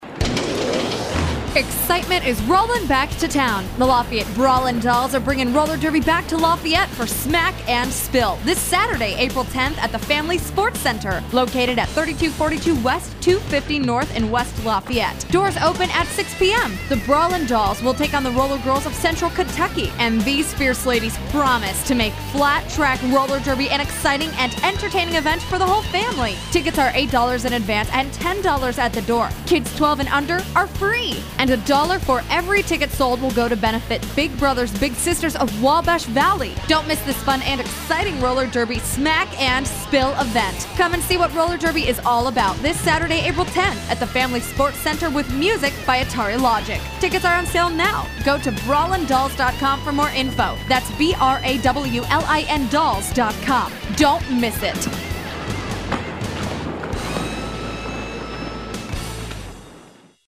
Brawlin’ Dolls Radio Commercial